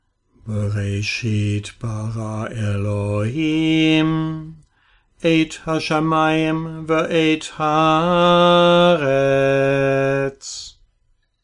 But in most formulations, etnachta goes up and sof pasuk goes down, like this.
Here goes Sing it with me a few times.